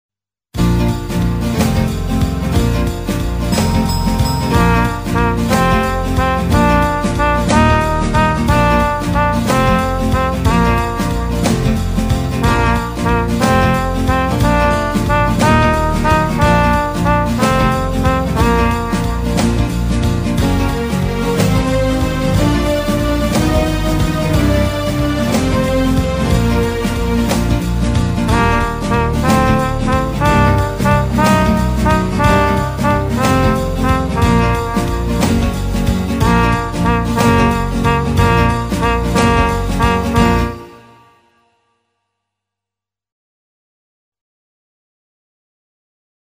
Recueil pour Trompette ou cornet